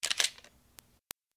camera_click.ogg